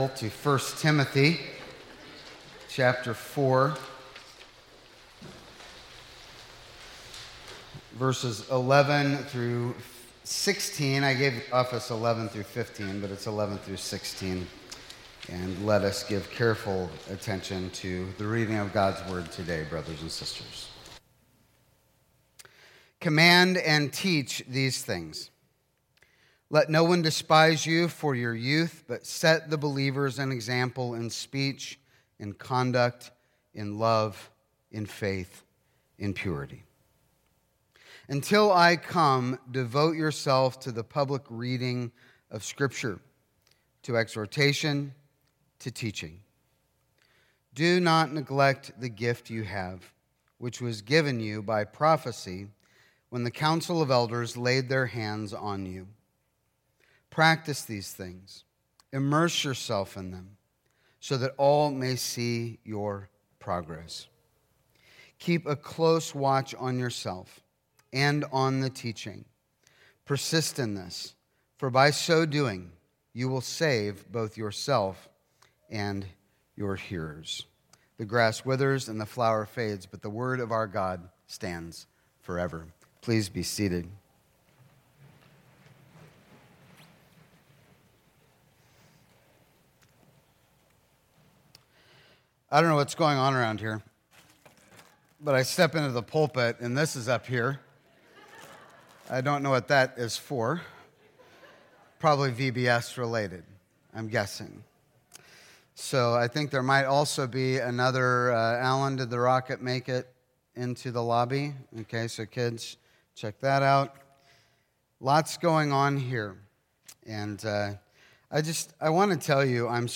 1 Timothy Sermons | New Life Presbyterian Church of La Mesa